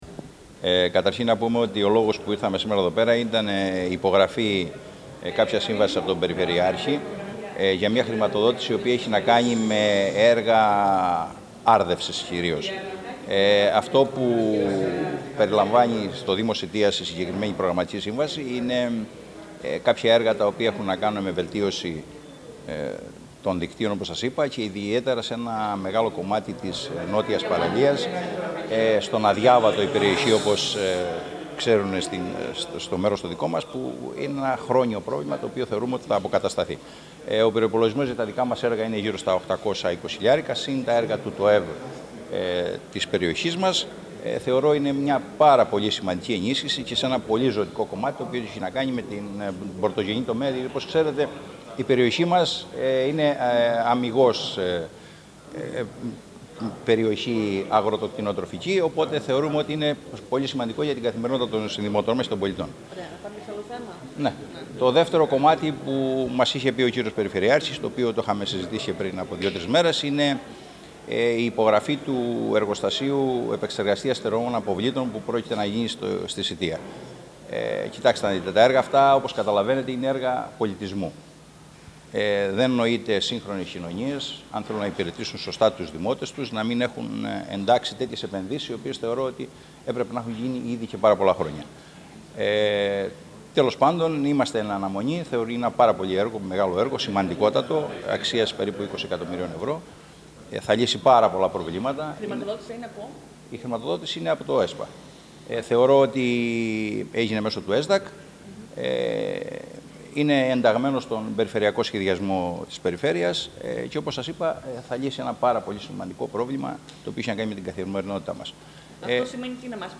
Σε δηλώσεις προέβη και ο Δήμαρχος Σητείας Γιώργος Ζερβάκης:
Ακούστε εδώ τις δηλώσεις του Δημάρχου Σητείας Γιώργου Ζερβάκη:
zervakis-shteia.mp3